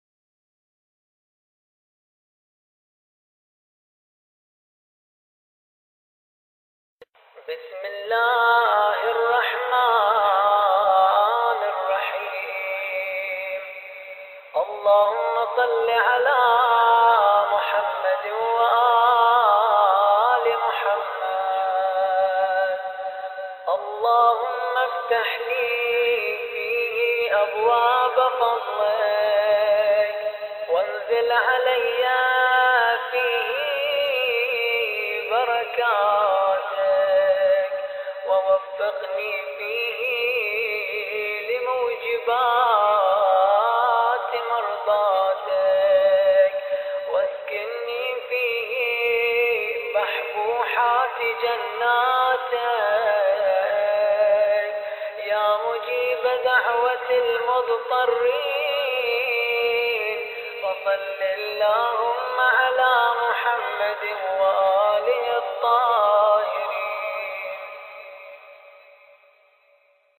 دعای روز بیست و دوم ماه مبارک رمضان